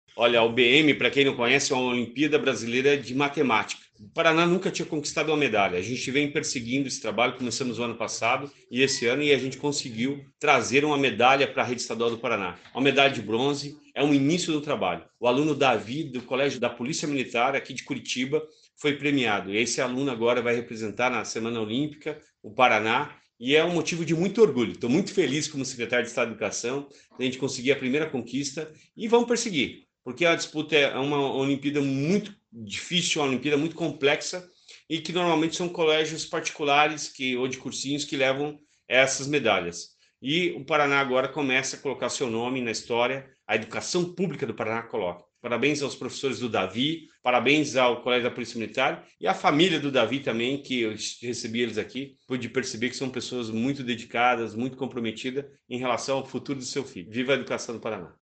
Sonora do secretário da Educação, Roni Miranda, sobre a conquista de medalha na Olimpíada Brasileira de Matemática por aluno da rede estadual